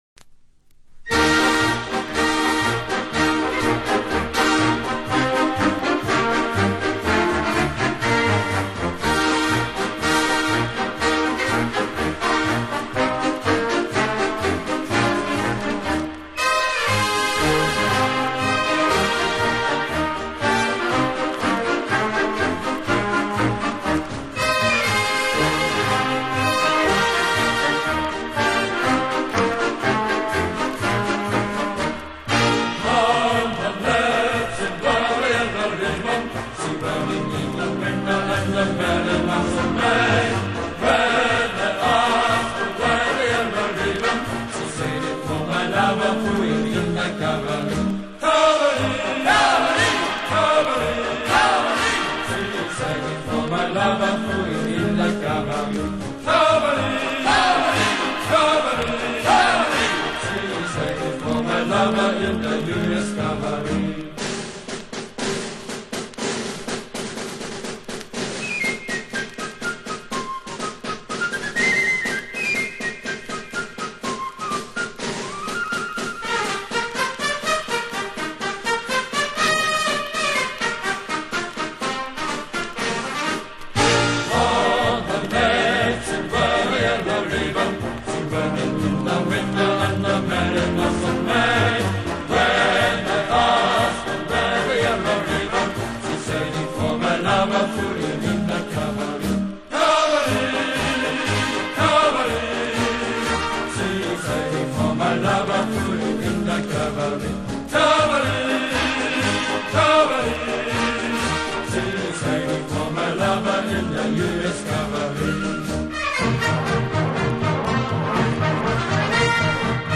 У японцев довольно красивая музыка (марши) времен войны.